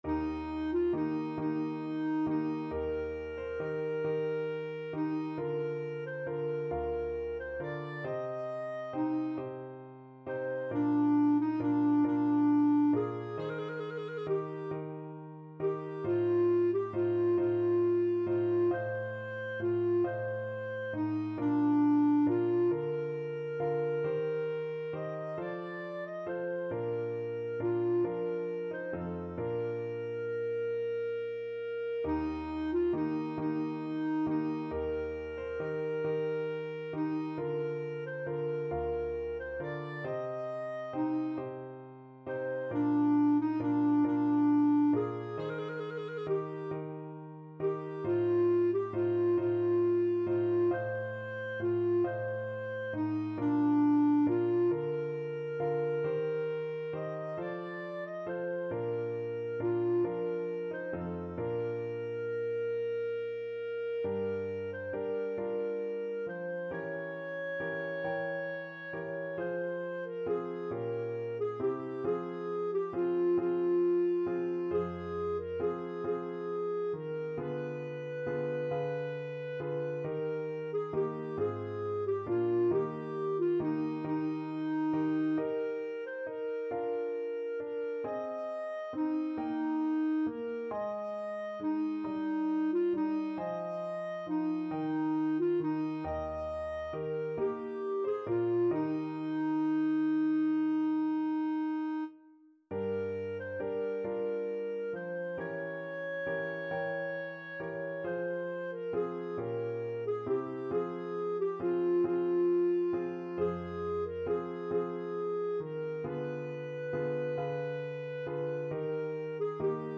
Classical Vivaldi, Antonio Concerto for Flute and String Orchestra 'Il Gardellino', Op.10, No.3, 2nd movement Clarinet version
Clarinet
Eb major (Sounding Pitch) F major (Clarinet in Bb) (View more Eb major Music for Clarinet )
12/8 (View more 12/8 Music)
II: Larghetto cantabile .=45
Classical (View more Classical Clarinet Music)